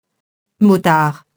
motard, motarde [mɔtar, -ard] nom (de moto)